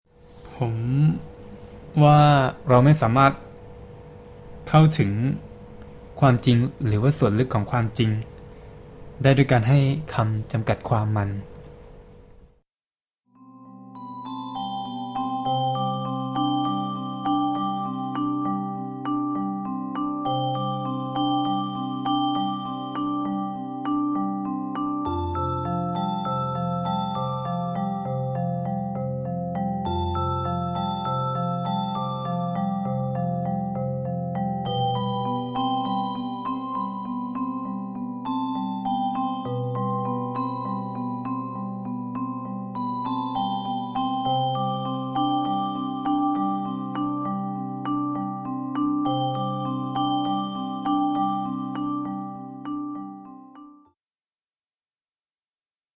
Burning incense sculpture, charcoal sphere, 188 voices
188 small mirror-like speakers broadcast different interviewees’ responses towards the ultimate question in a murmuring ambience, because of the many different languages, provoking a very strange Tower of Babel mood around Mars.
This project is part of The Third Guangzhou Triennale, Guangdong Museum of Art, 2008